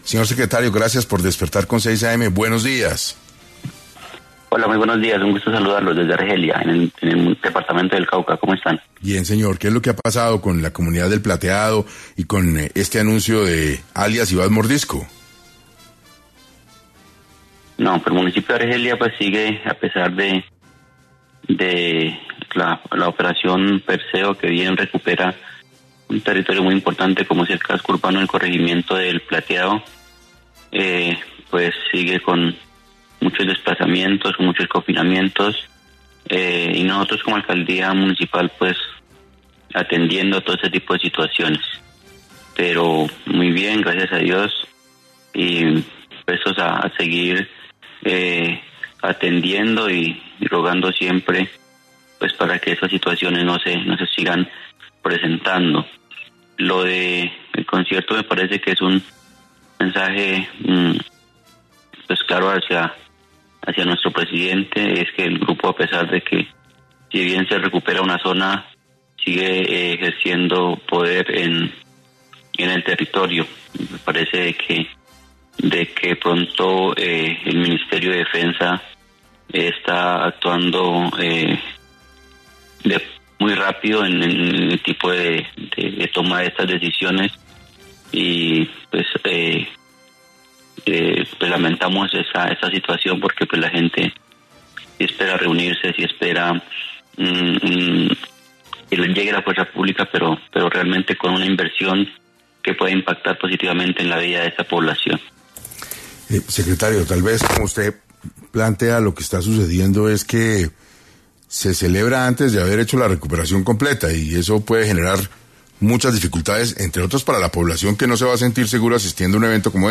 En 6 AM de Caracol Radio estuvo Paulo Daza, secretario de Gobierno de Argelia, quien habló sobre por qué el Gobierno insiste en proponer un concierto en medio del conflicto que viven los habitantes de El Plateado.